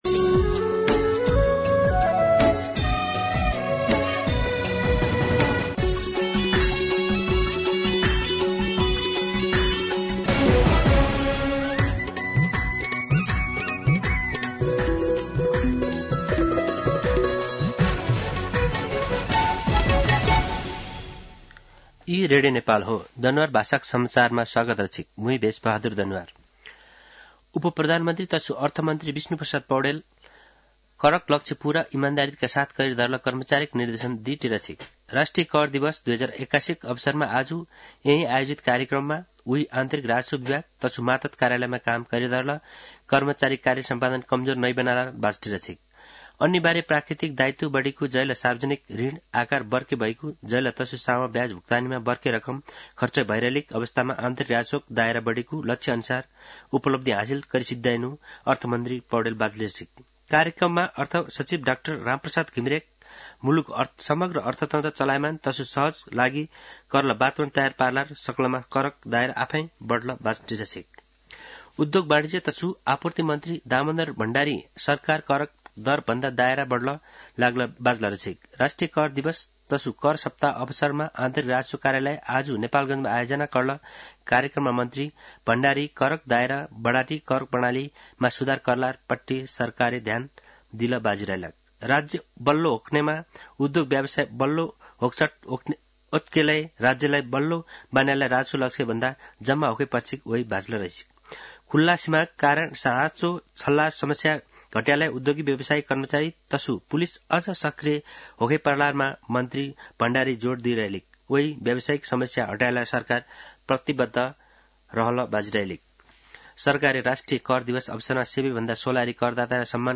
दनुवार भाषामा समाचार : २ मंसिर , २०८१
Danuwar-News-01.mp3